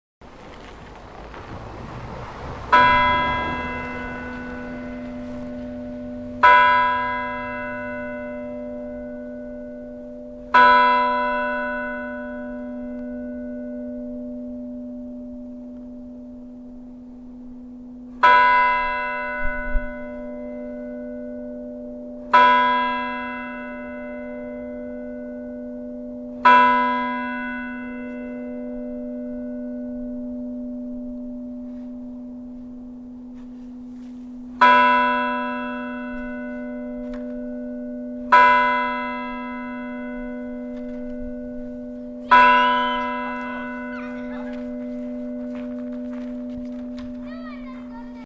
Í kirkjunni eru þrjár klukkur sem voru keyptar og settar upp árið 1991.
Fyrir útför eru bænaslögin, 3 x 3 slög, slegin í stærstu klukkuna en ekki er hringt við lok útfarar.
fella_og_holakirkja_baenaslög.mp3